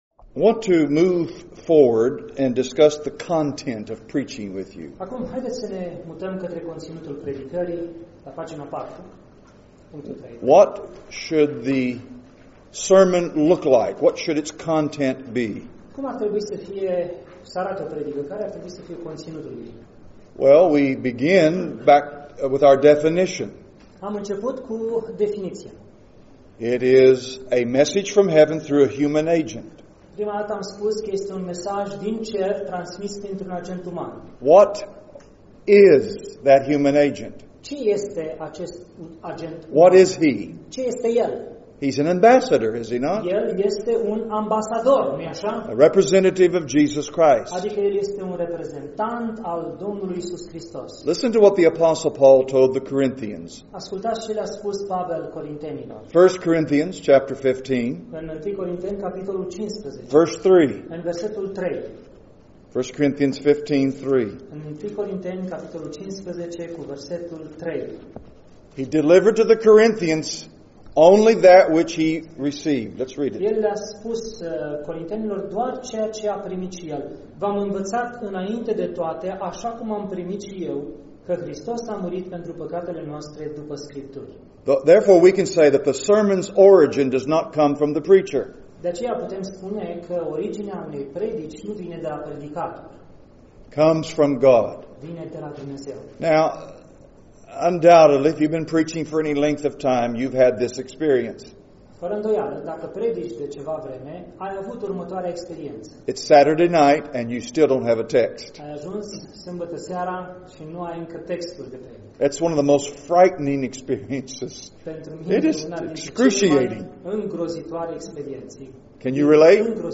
Categorie: Predici Complete